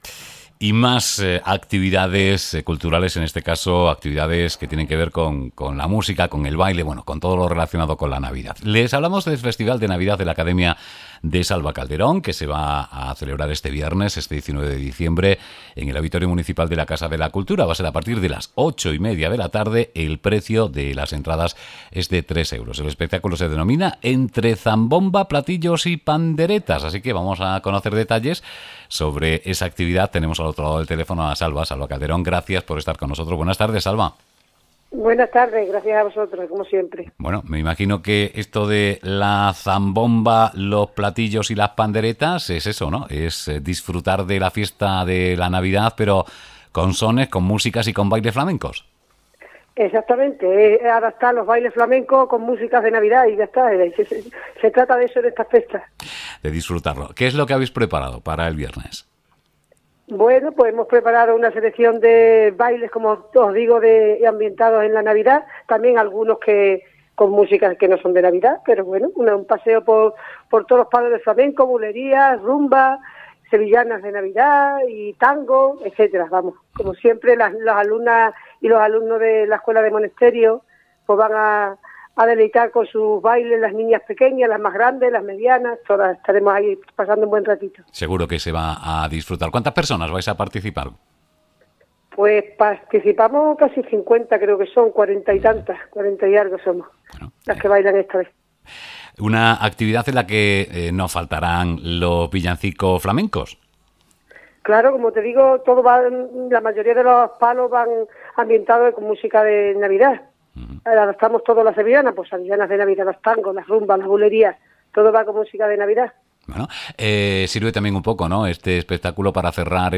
Pasión flamenca y espíritu festivo en el festival navideño